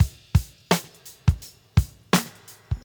85 Bpm 70's Jazz+Funk Drum Beat F# Key.wav
Free breakbeat - kick tuned to the F# note. Loudest frequency: 1885Hz
85-bpm-70s-jazz+funk-drum-beat-f-sharp-key-2lD.ogg